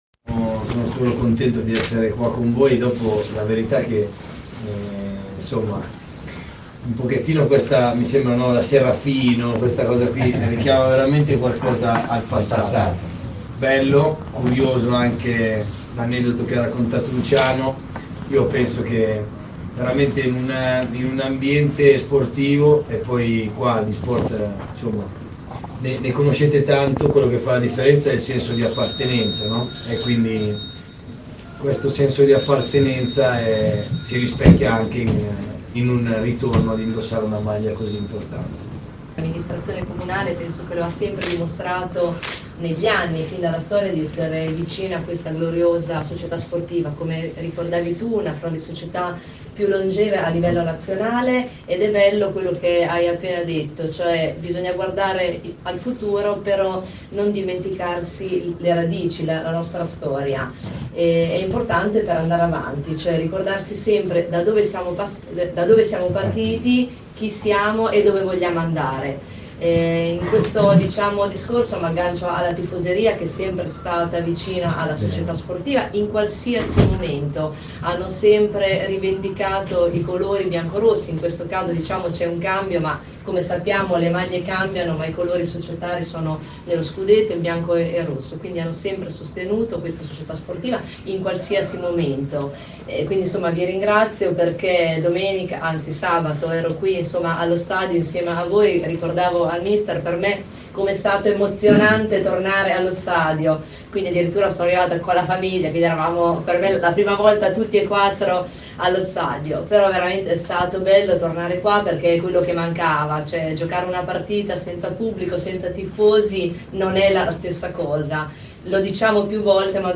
Allo Stadio Tonino Benelli è stata presentata la nuova seconda maglia della Vis Pesaro, che richiama i colori primordiali della società Biancorossa.